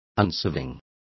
Complete with pronunciation of the translation of unswerving.